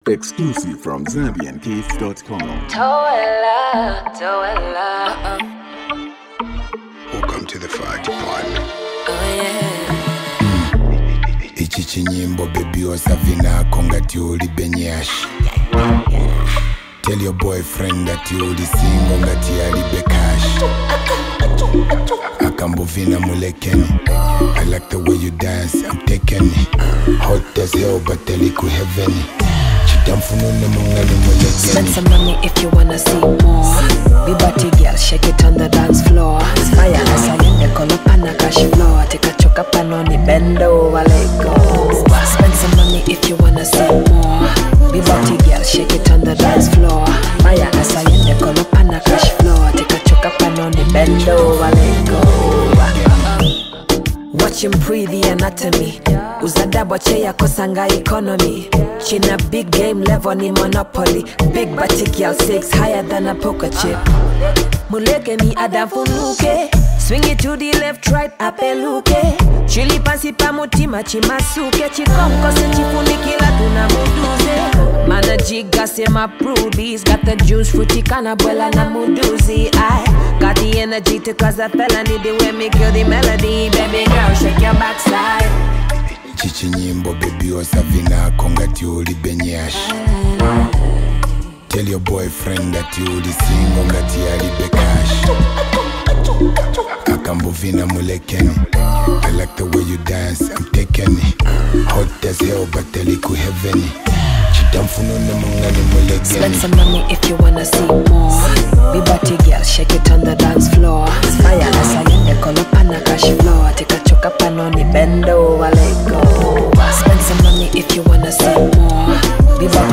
rapper
creating a perfect balance of melody and rap.
With its catchy rhythm